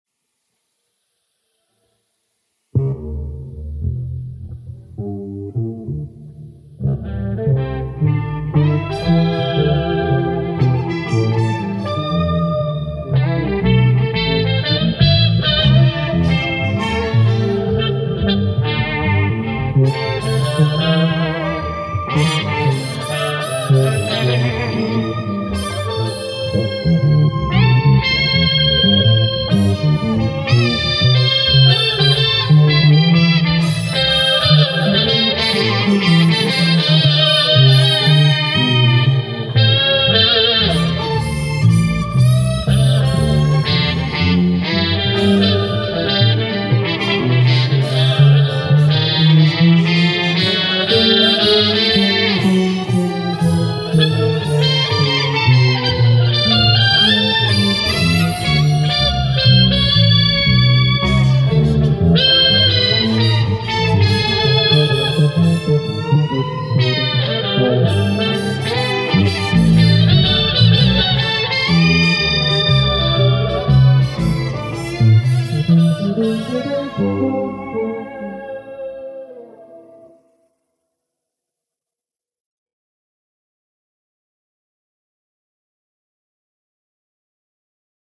synth-heavy off-the-wall blues